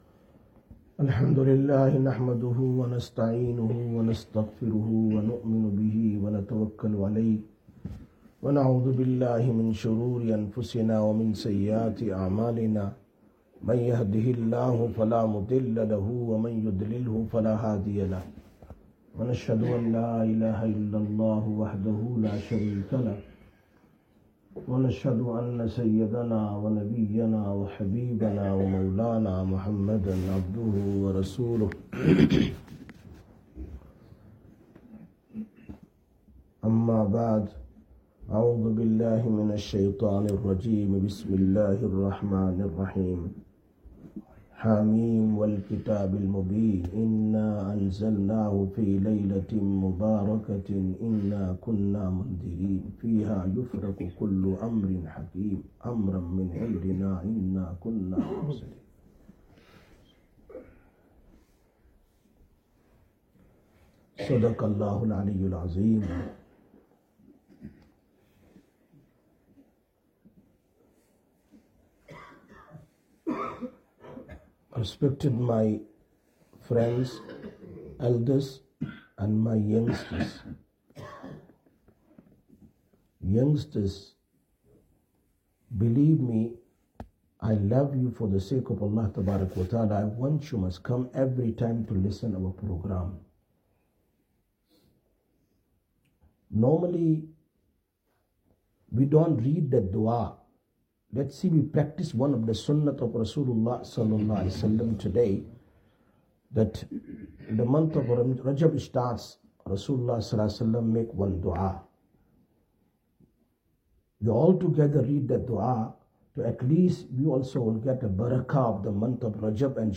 02/02/2026 Shab e Barat Bayaan, Masjid Quba